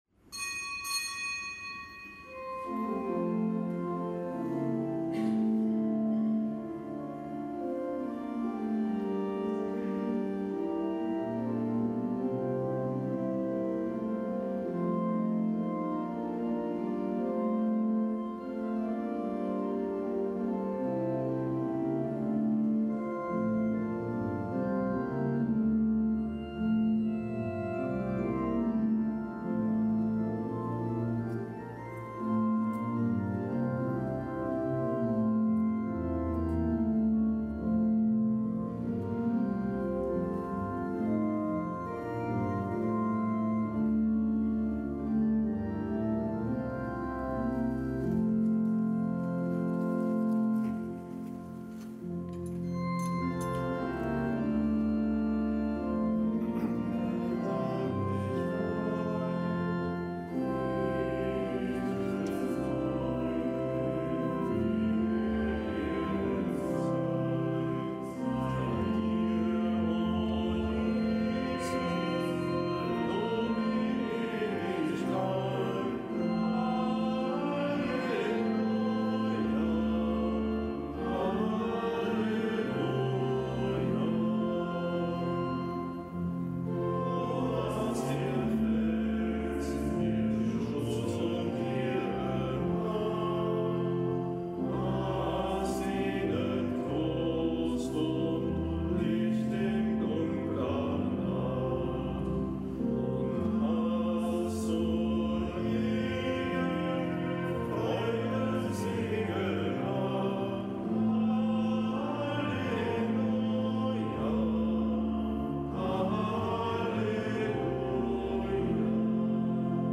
Kapitelsmesse am Fest des Heiligen Maternus
Kapitelsmesse aus dem Kölner Dom am Fest des Heiligen Maternus, erster bekannter Bischof von Köln (DK)